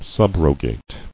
[sub·ro·gate]